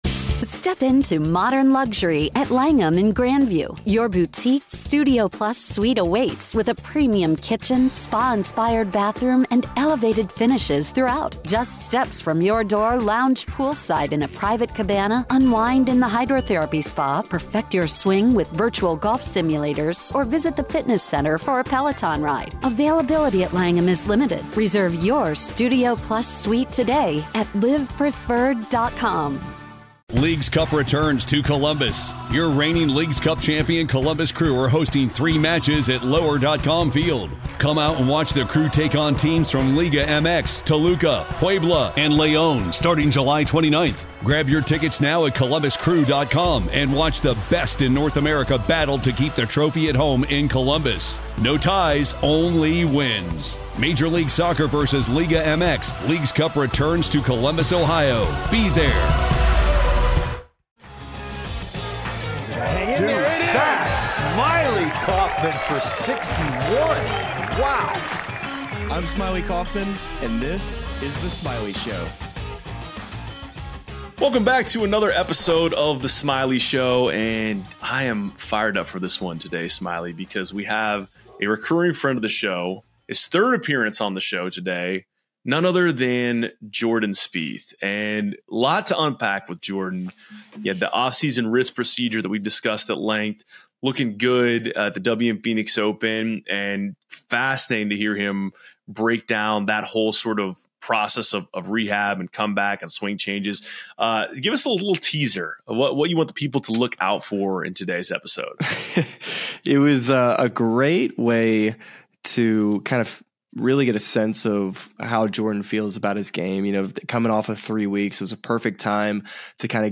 Jordan Spieth Interview: Comeback from Wrist Surgery + Returning To Swing DNA